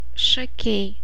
pronunciation) is a city in the Marijampolė County, Lithuania.[1] It is located 65 km (40 mi) west of Kaunas.